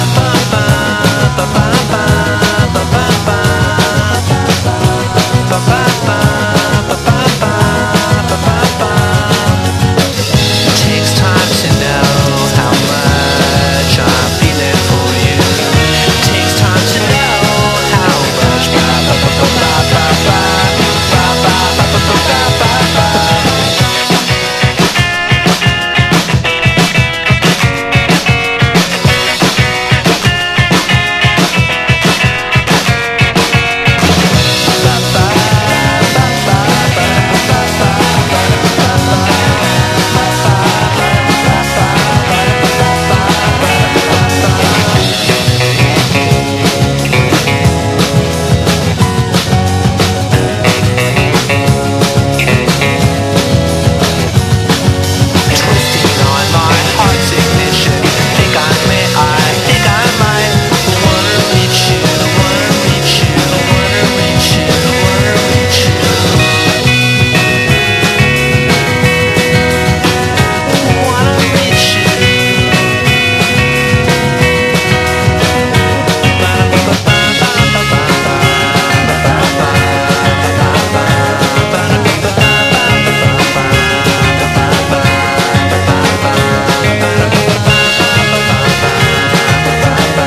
BREAKBEATS/HOUSE / HCFDM / BIG BEAT / INDIE POP / 90'S